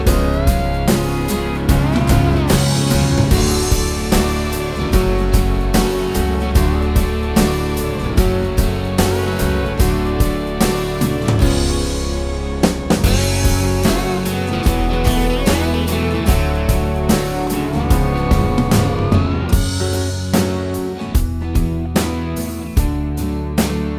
no Backing Vocals Country (Female) 3:07 Buy £1.50